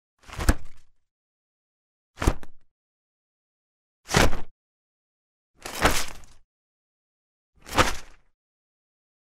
Звуки зонта
1. Звук раскрытия зонта n2. Шум открывающегося зонта